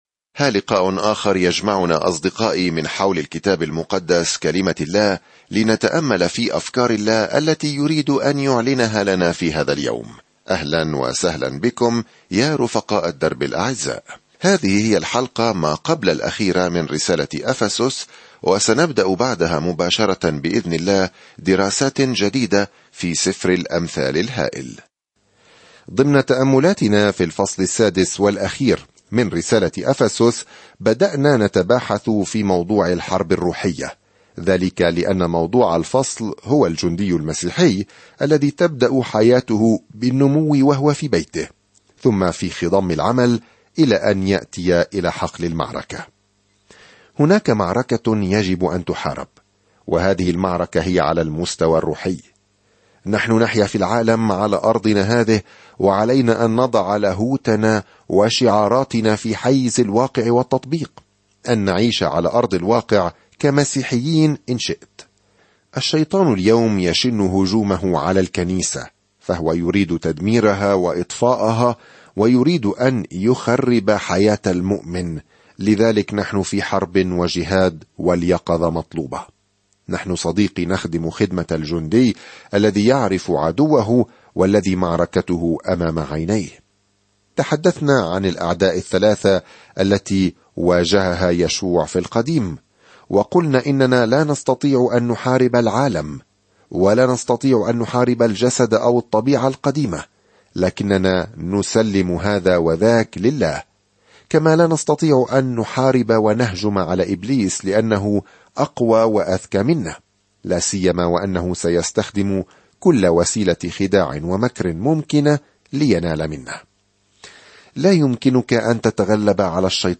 الكلمة أَفَسُسَ 13:6-15 يوم 26 ابدأ هذه الخطة يوم 28 عن هذه الخطة من الأعالي الجميلة لما يريده الله لأولاده، تشرح الرسالة إلى أهل أفسس كيفية السلوك في نعمة الله وسلامه ومحبته. سافر يوميًا عبر رسالة أفسس وأنت تستمع إلى الدراسة الصوتية وتقرأ آيات مختارة من كلمة الله.